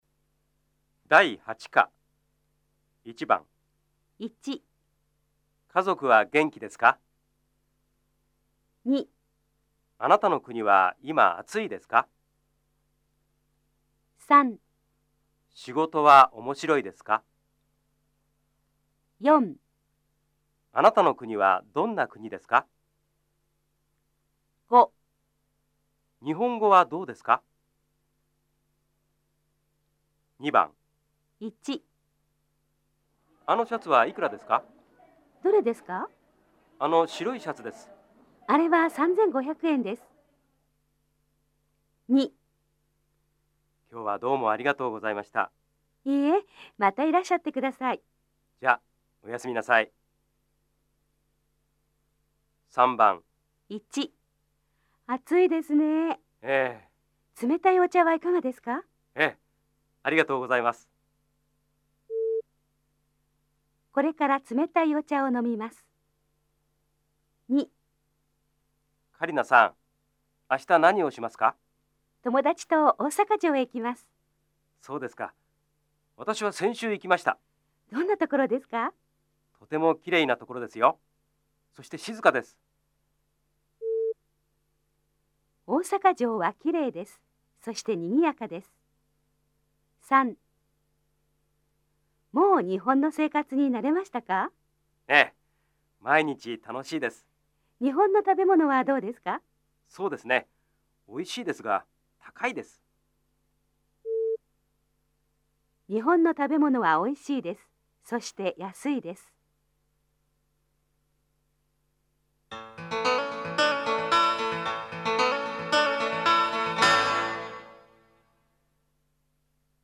大家的日语-第8课听力练习